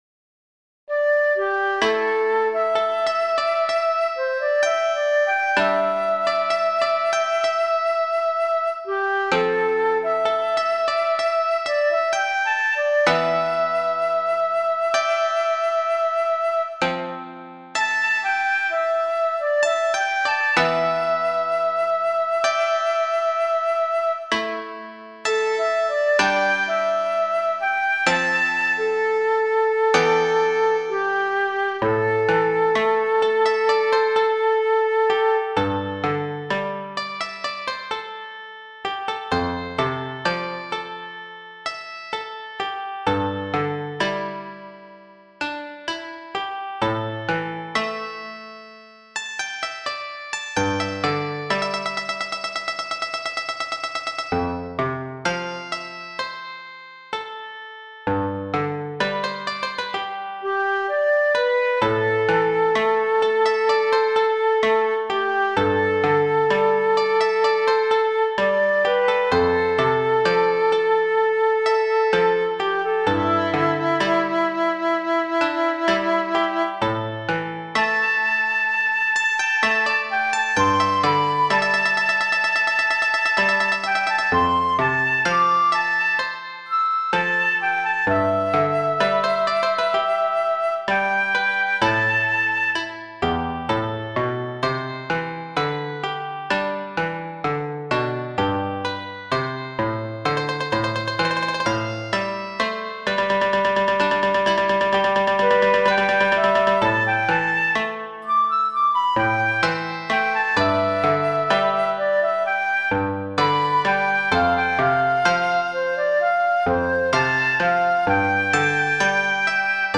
【編成】箏・十七絃・尺八（一尺六寸） 風を浴びた時に浮かんだフレーズを発展させて、三重奏曲に仕上げてみました。
スローテンポな前半から、尺八のソロを経て、吹き抜ける後半へと展開していきます。
風を浴びた時に浮かんだフレーズを発展させて、三重奏曲に仕上げてみました。